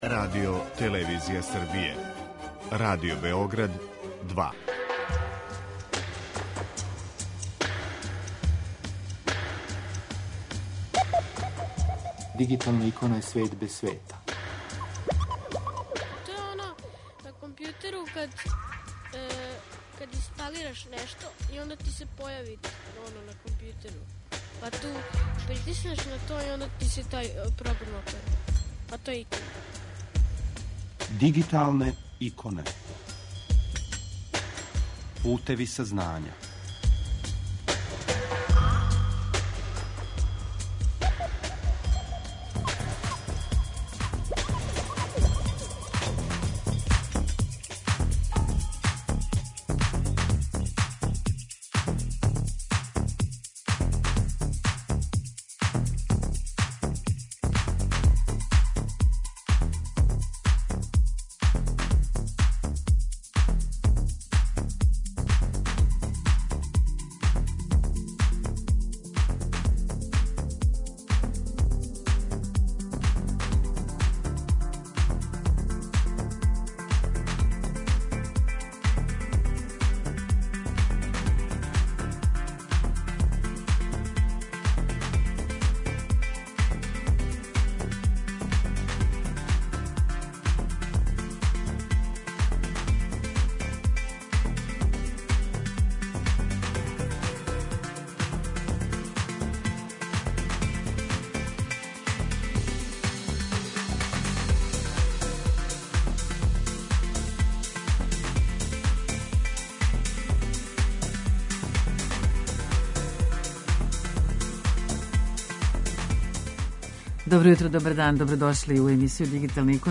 У данашњој емисији Дигиталне иконе са нама уживо